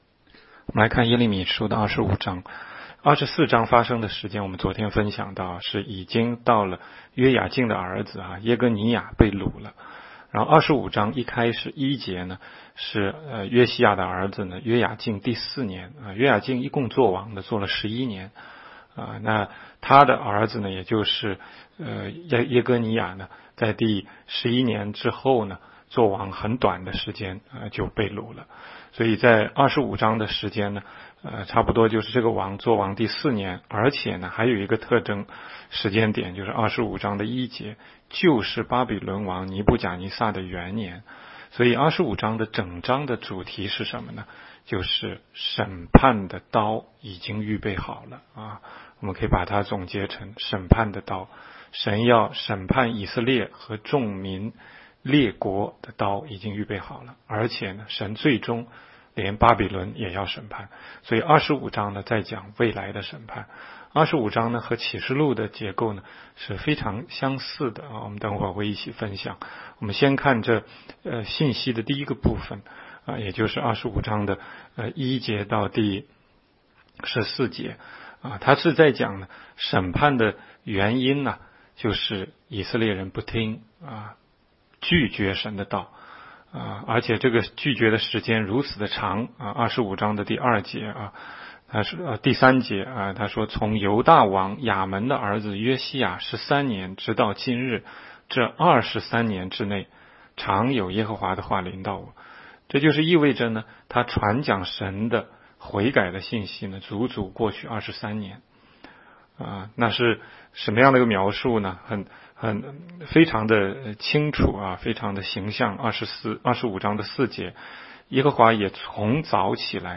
16街讲道录音 - 每日读经 -《耶利米书》25章